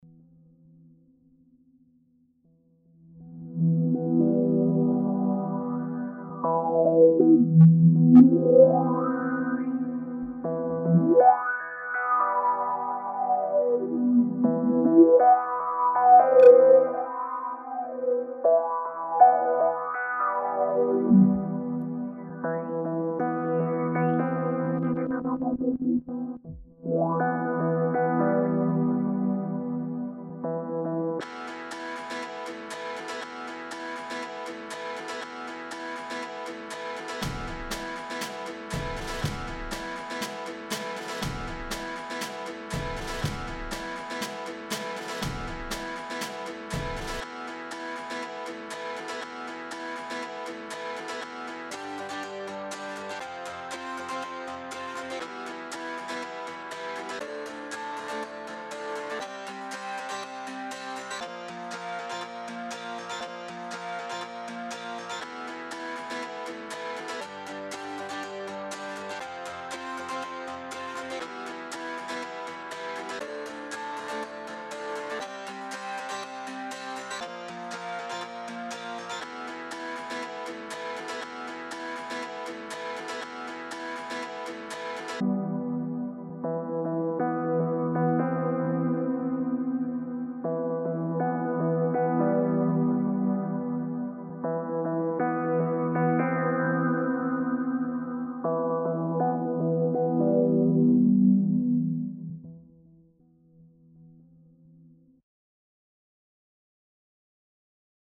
• Жанр: Рок
Текст у данной песни отсутствует.